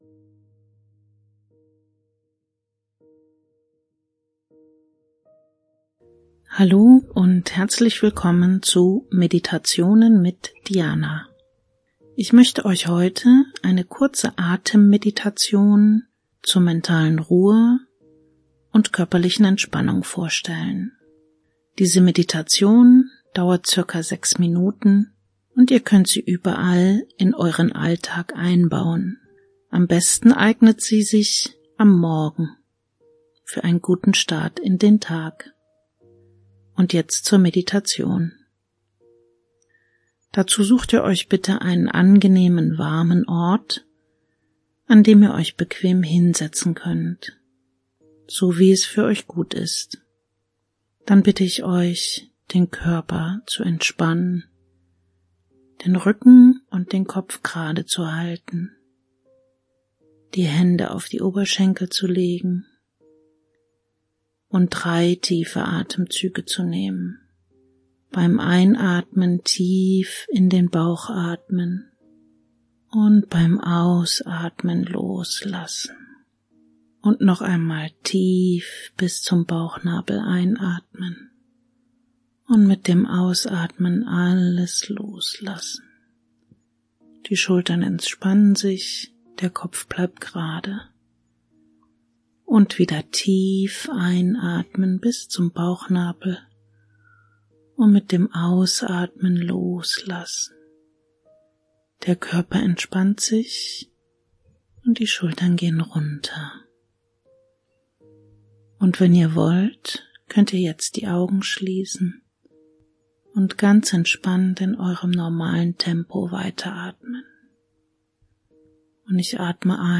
Deine Ruhe - Meditation